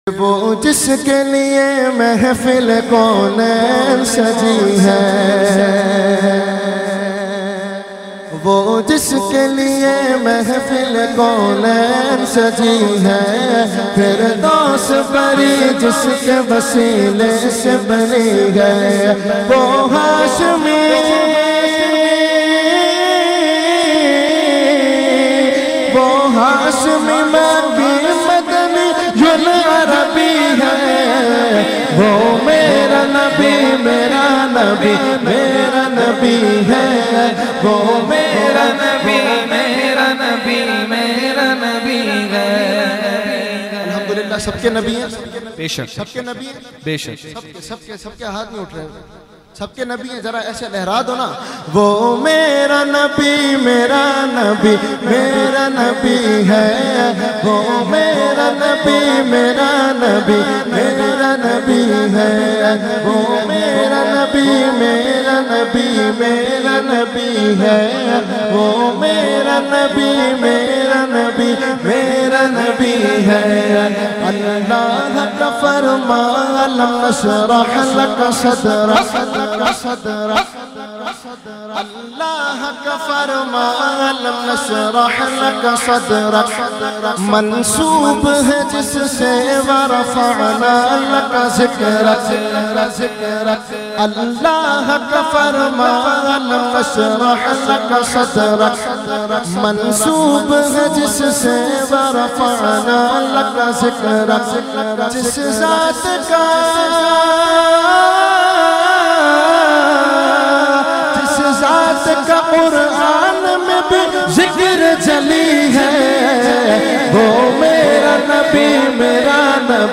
Category : Naat | Language : UrduEvent : Muharram 2020